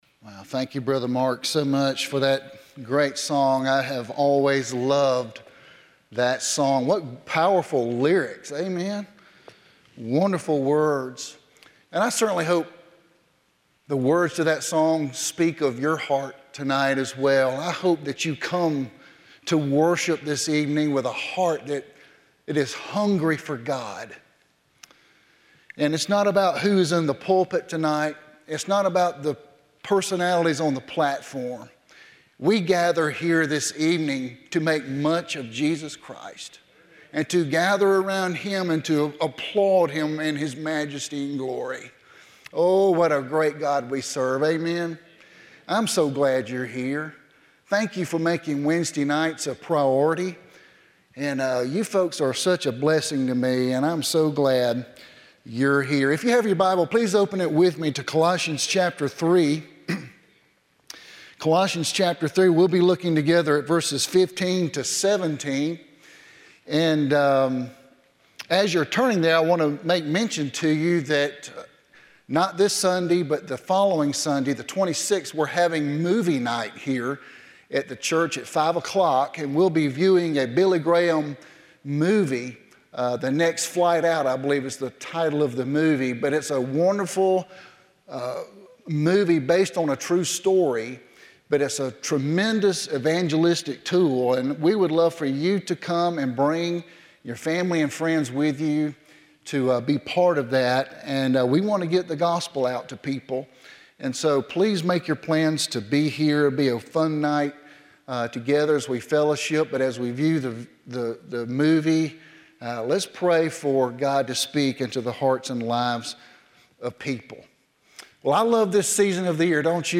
11-15-12pm Sermon – Thanks Living